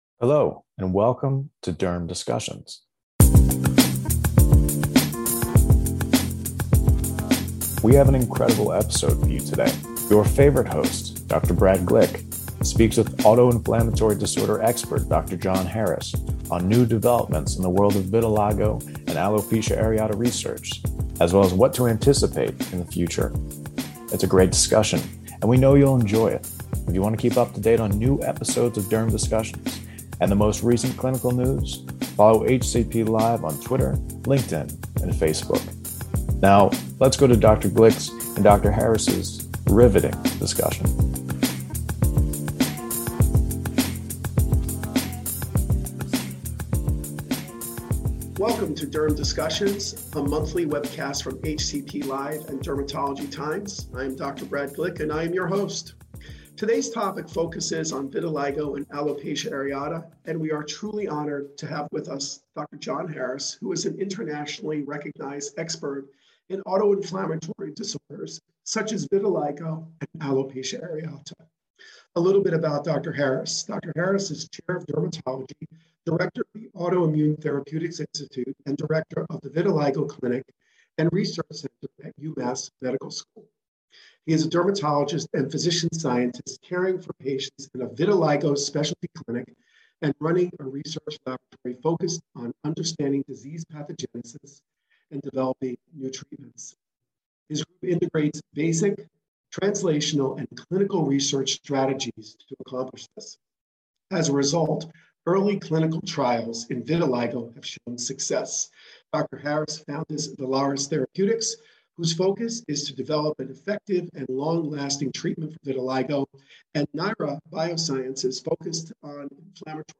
In this Derm Discussions, experts review the state of clinical innovation driving new opportunities in chronic skin disease care.